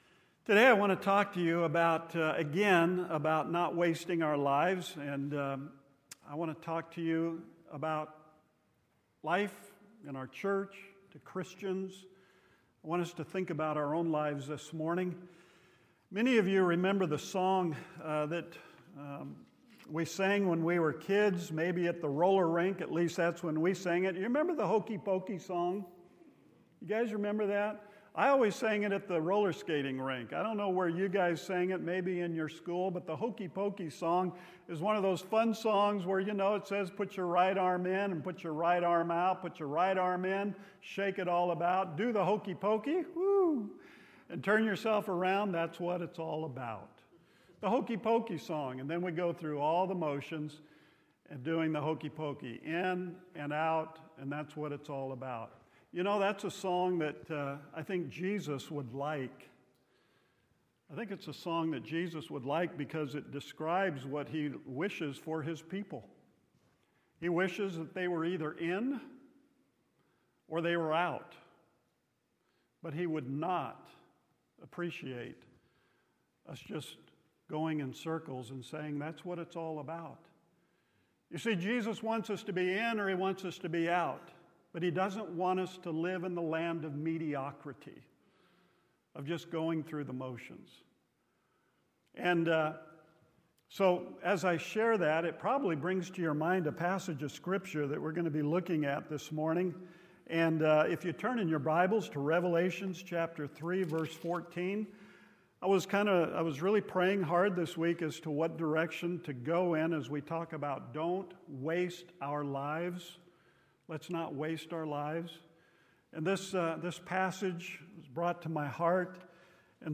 Central Baptist Church Sermons